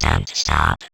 VVE1 Vocoder Phrases 13.wav